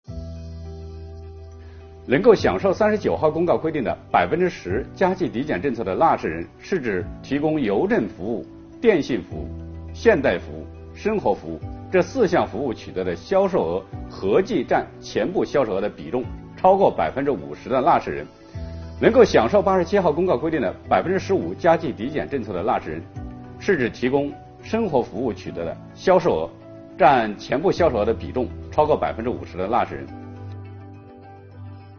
本期课程由国家税务总局货物和劳务税司副司长刘运毛担任主讲人，对2022年服务业领域困难行业纾困发展有关增值税政策进行详细讲解，方便广大纳税人更好地理解和享受政策。